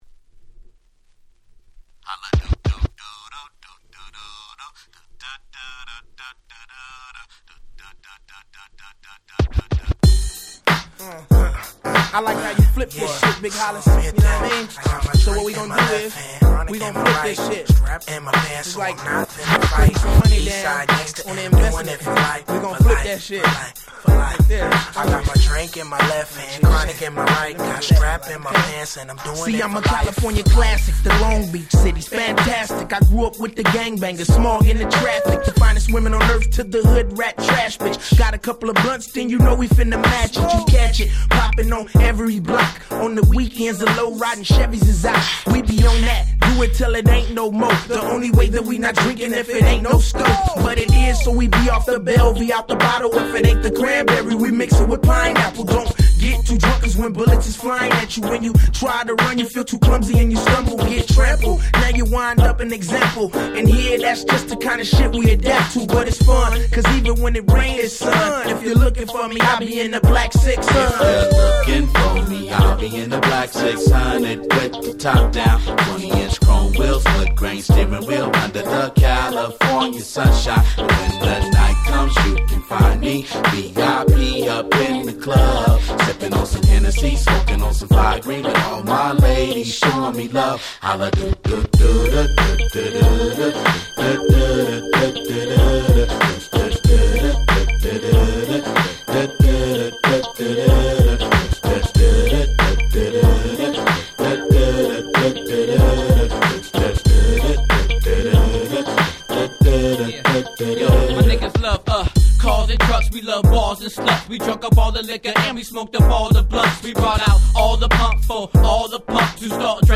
03' Very Nice West Coast Hip Hop !!
G-Rap Gangsta Rap